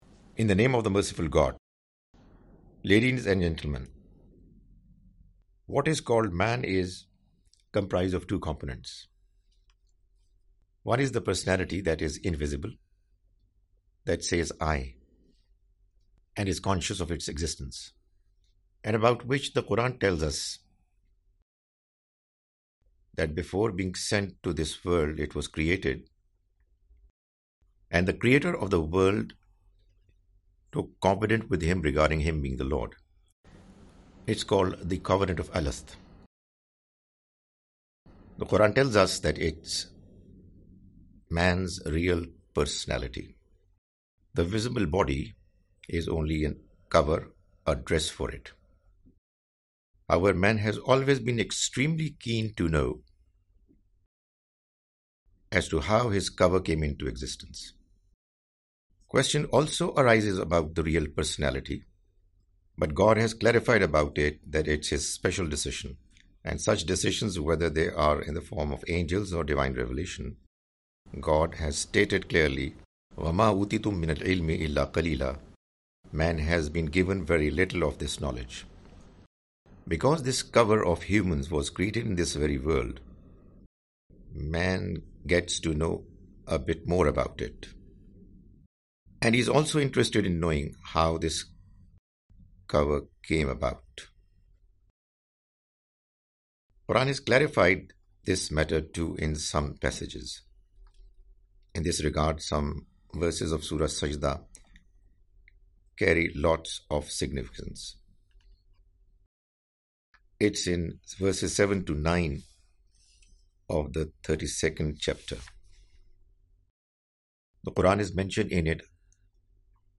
The Message of Qur'an (With English Voice Over) Part-10
The Message of the Quran is a lecture series comprising Urdu lectures of Mr Javed Ahmad Ghamidi.